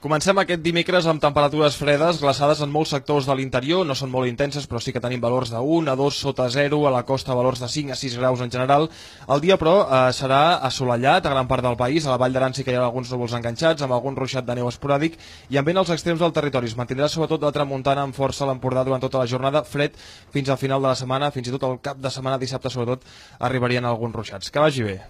Nom programa La méteo Descripció Estat i previsió del temps Gènere radiofònic Informatiu